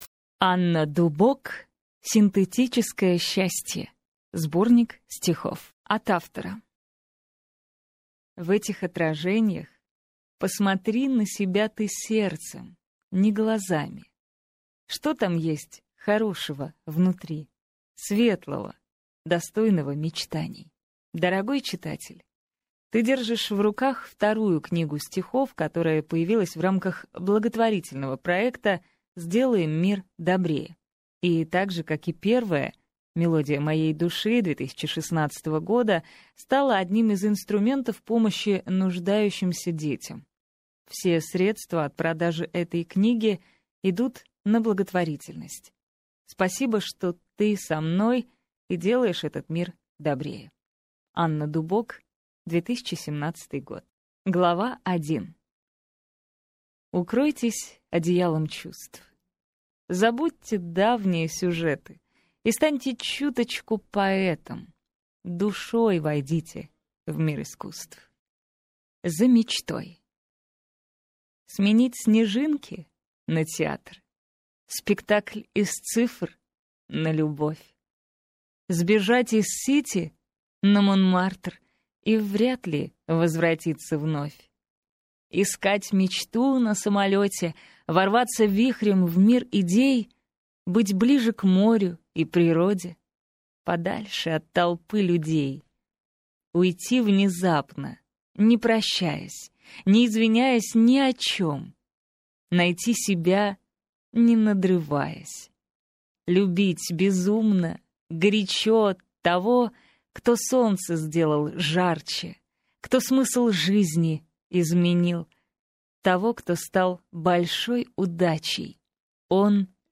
Аудиокнига Синтетическое счастье. Сборник стихов | Библиотека аудиокниг